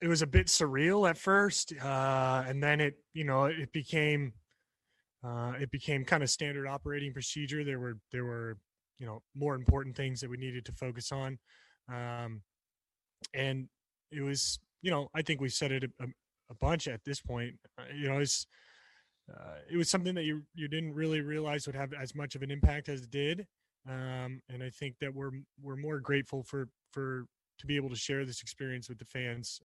Though the journey was hectic, in the end the Los Angeles Dodgers were crowned the 2020 World Series Champions. 3-time All Star and star pitcher for the New York Yankees, Gerrit Cole spoke to the media about the impact of empty stadiums in 2020.